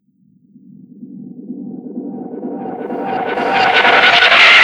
FADEINREV -L.wav